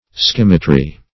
Meaning of skimitry. skimitry synonyms, pronunciation, spelling and more from Free Dictionary.
Skimitry \Skim"i*try\, n.